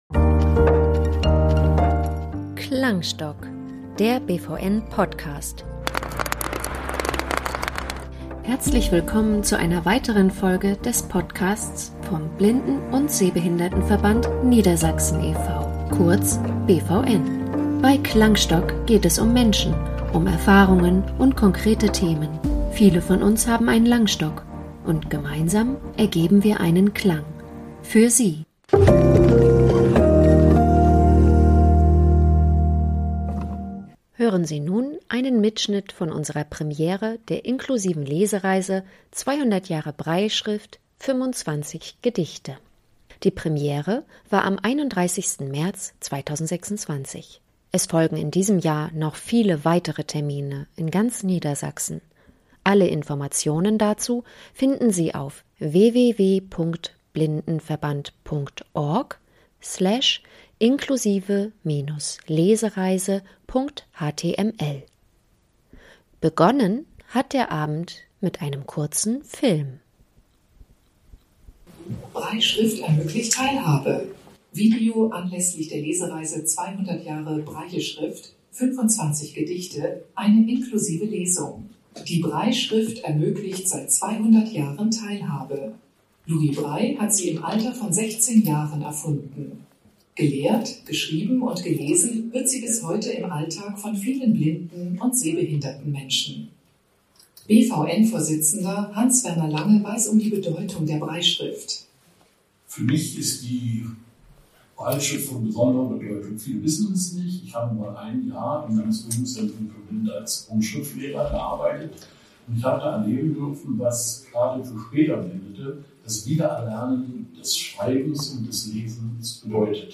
Seien Sie bei der Premiere unserer inklusiven Lesereise in Hannover dabei.